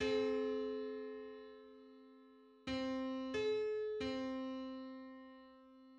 Just: 837/512 = 850.90 cents.
Public domain Public domain false false This media depicts a musical interval outside of a specific musical context.
Eight-hundred-thirty-seventh_harmonic_on_C.mid.mp3